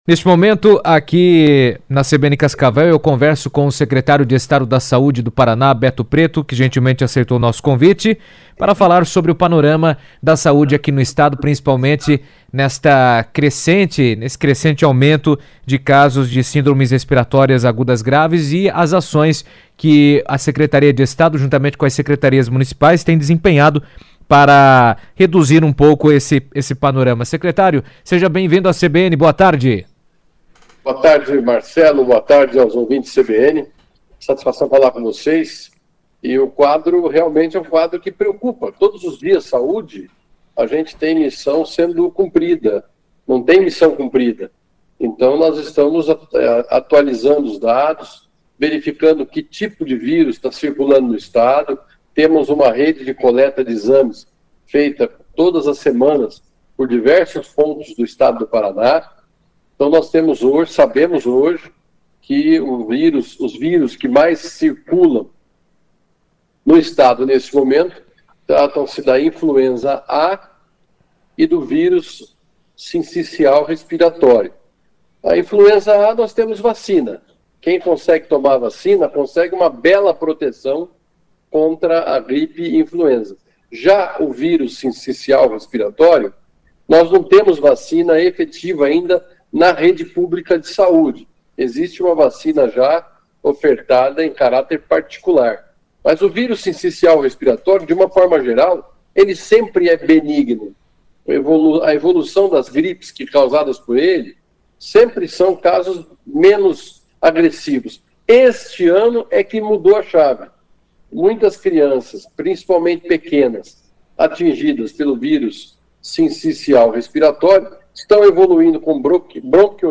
Em entrevista à CBN Cascavel, o secretário estadual da Saúde, Beto Preto, destacou a importância da vacinação como principal forma de prevenção, especialmente neste período em que os vírus respiratórios circulam com maior intensidade.